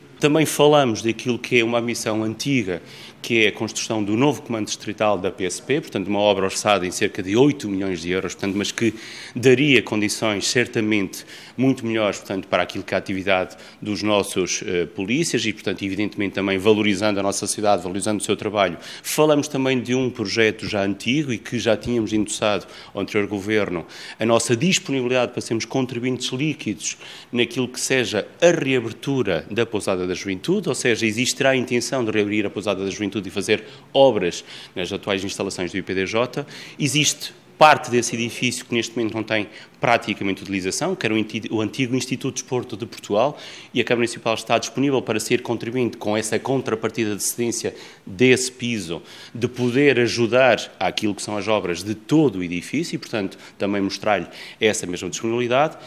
O novo comando distrital da Polícia de Segurança Pública e a reabertura da Pousada da Juventude foram outras preocupações manifestadas por Alexandre Favaios a Miguel Pinto Luz: